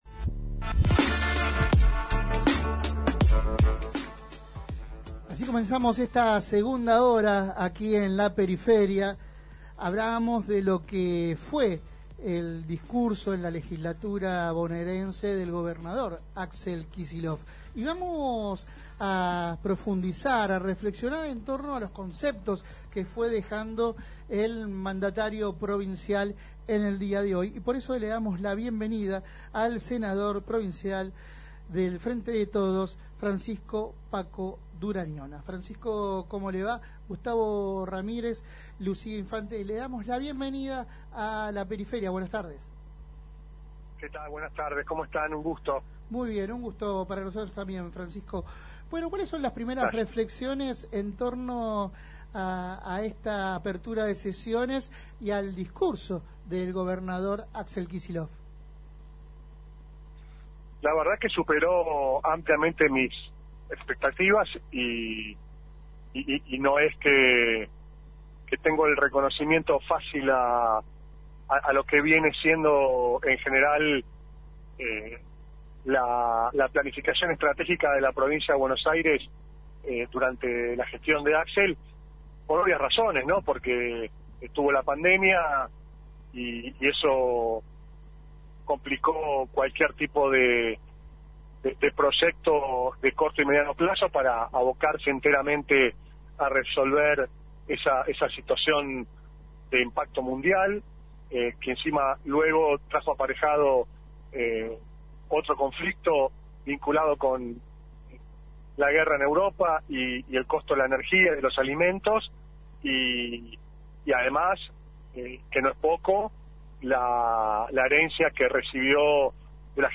Compartimos la entrevista completa: 06/3/2023